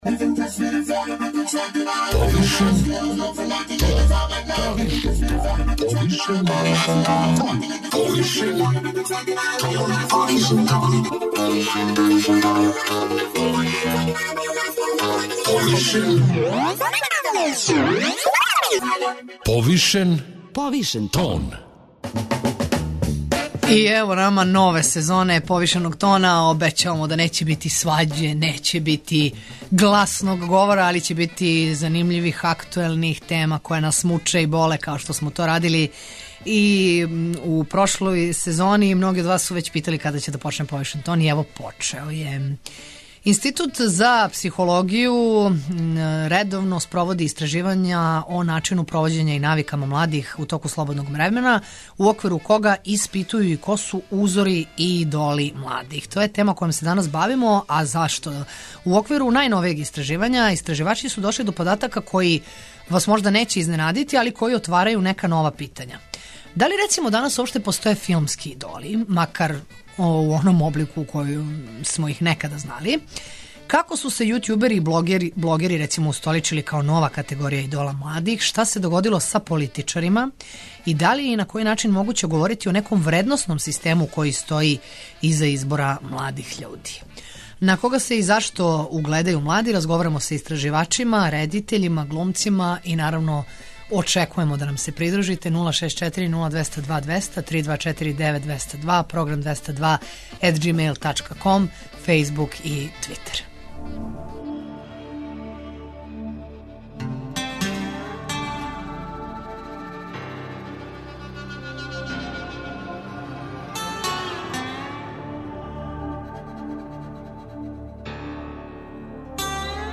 На кога се и зашто угледају млади разговарамо са истраживачима, редитељима, глумцима, окечујемо да нам се придружите.